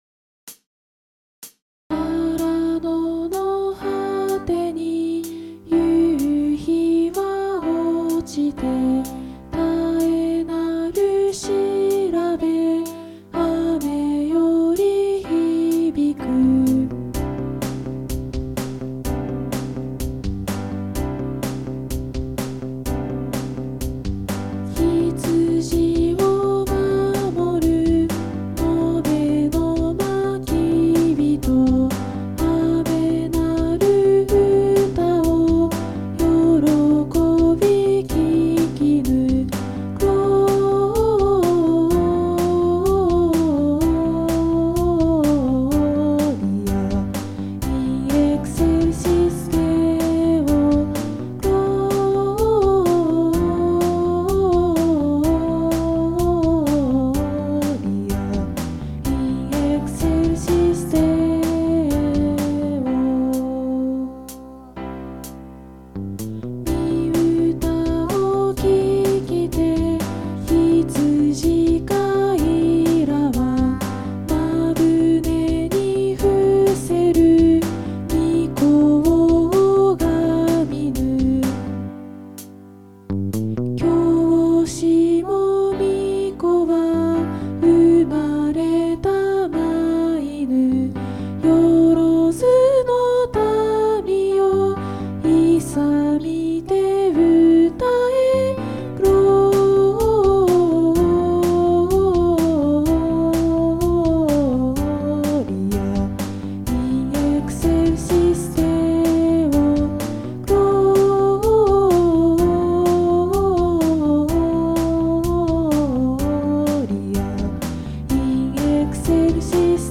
2022年音声劇＿れんしゅう用音源